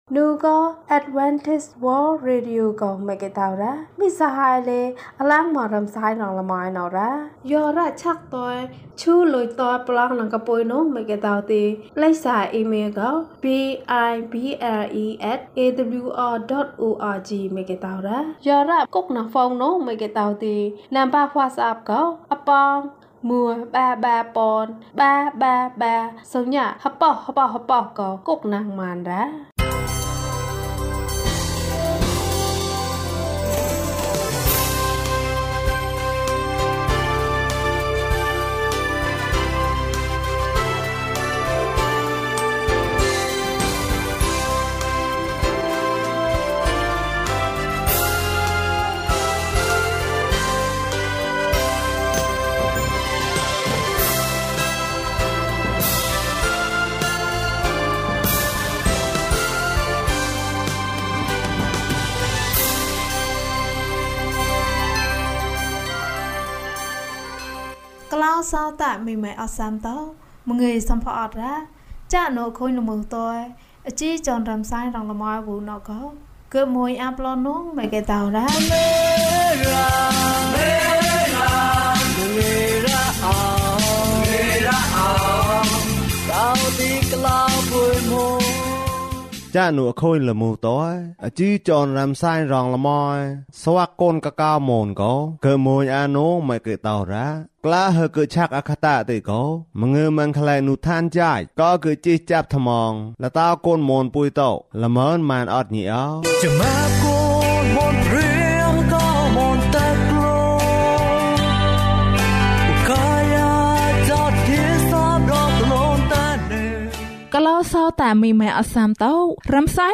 ခရစ်တော်ထံသို့ ခြေလှမ်း။၄၁ ကျန်းမာခြင်းအကြောင်းအရာ။ ပုံပြင်။ ဓမ္မသီချင်း။ တရားဒေသနာ။